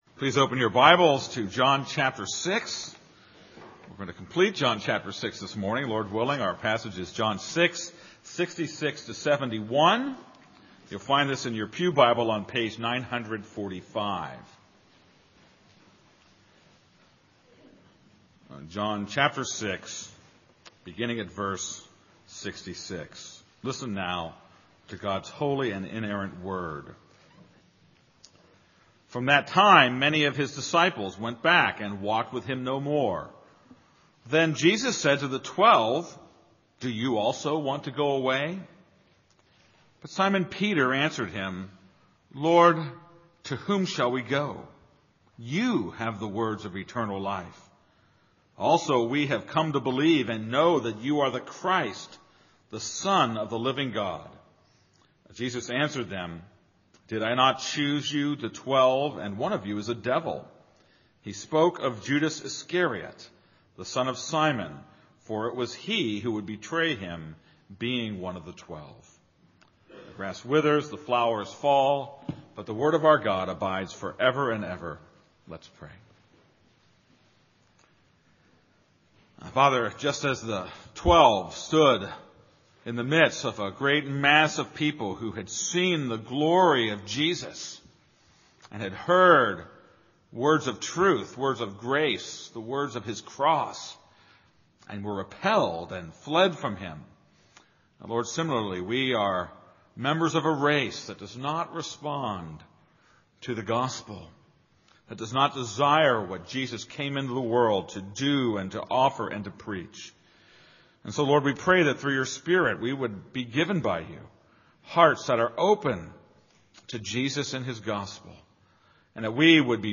This is a sermon on John 6:66-71.